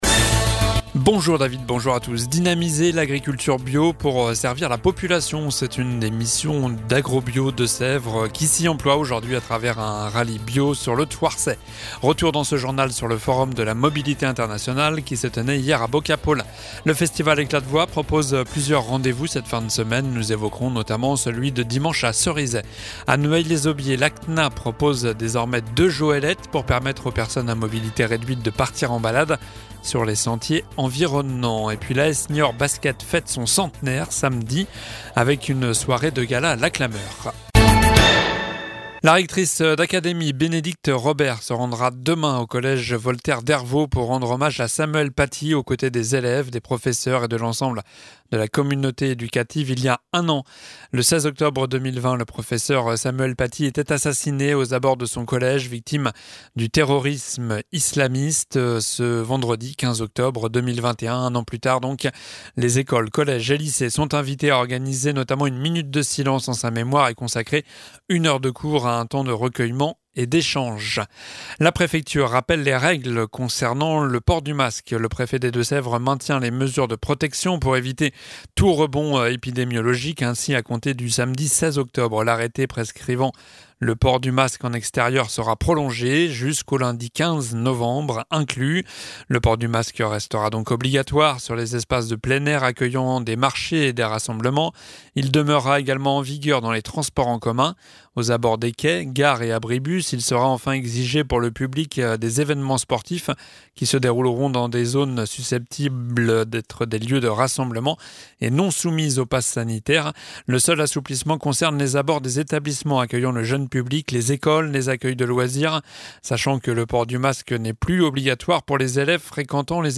Journal du jeudi 14 octobre (midi)